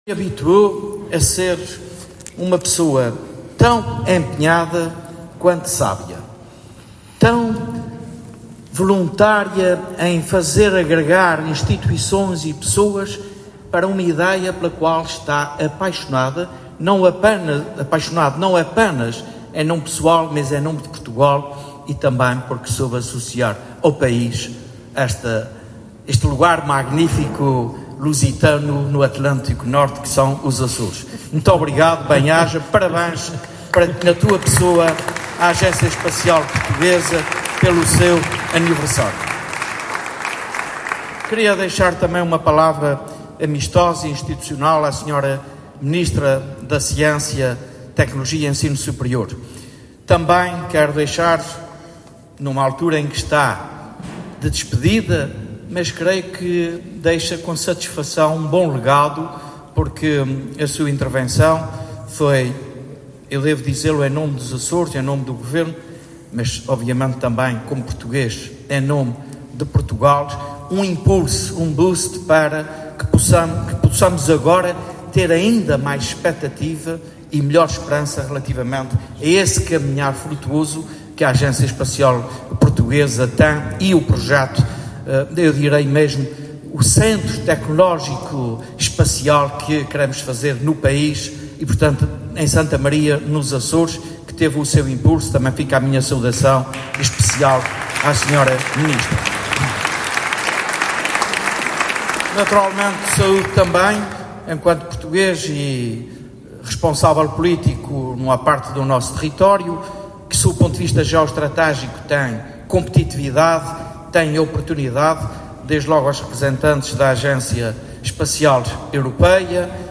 José Manuel Bolieiro spoke at the Estufa Fria in Lisbon, at a session where he was accompanied by the Regional Secretary for Parliamentary Affairs and Communities, Paulo Estêvão.
The President of the Regional Government, José Manuel Bolieiro, attended the ceremony marking the fifth anniversary of the Portuguese Space Agency on Monday evening, reiterating the Government's commitment to the sector.